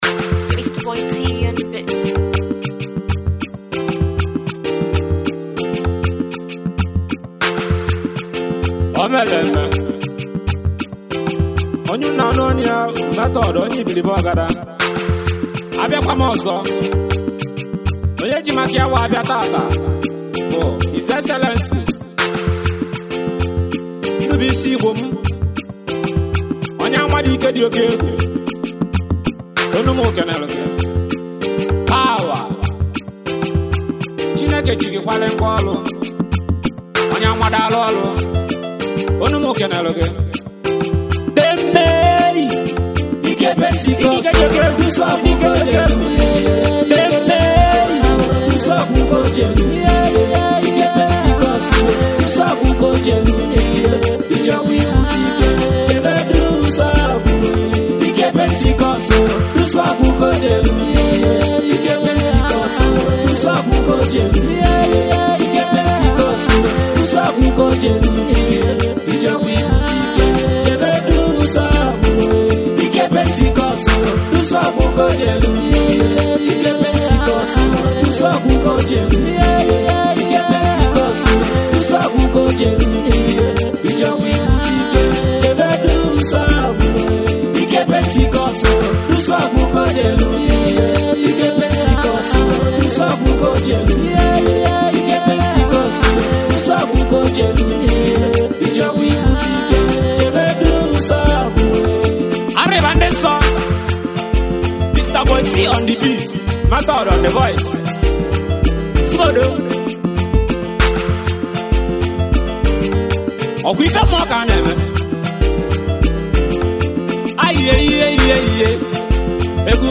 high life